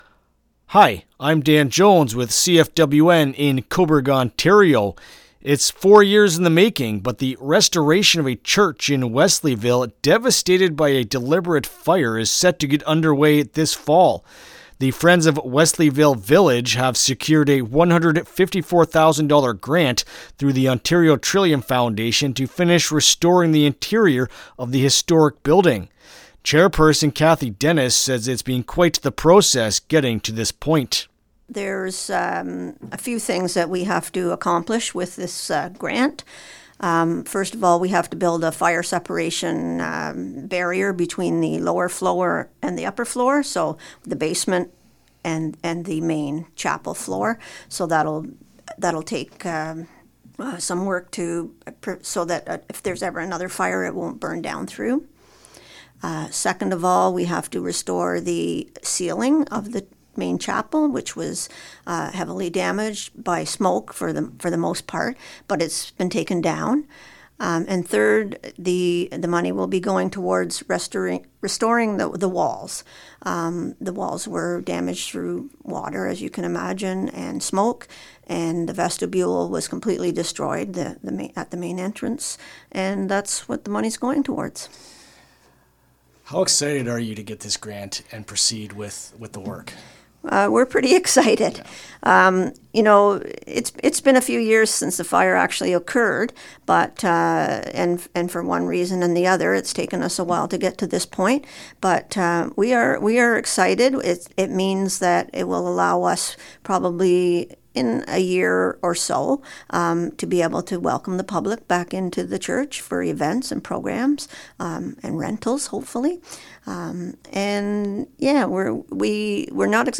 Wesleyville-Church-Interview-LJI.mp3